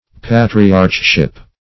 Patriarchship \Pa"tri*arch*ship\, n.